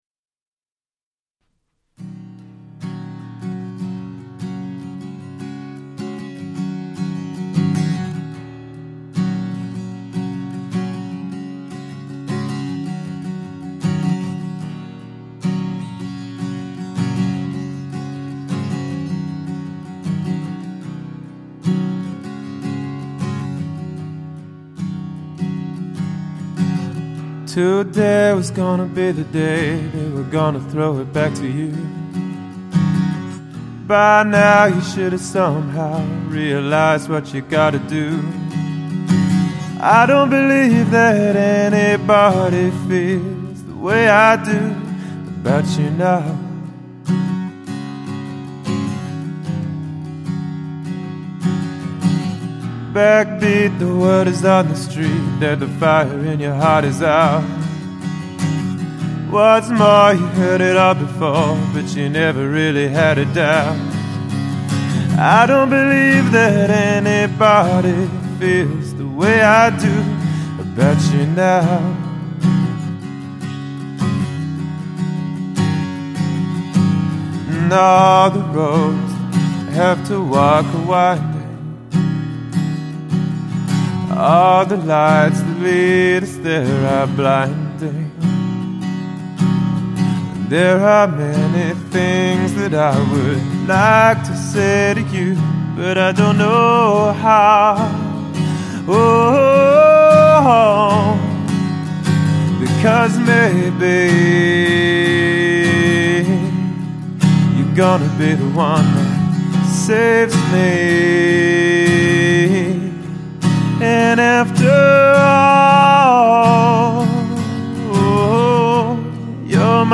• Unique and mellow voice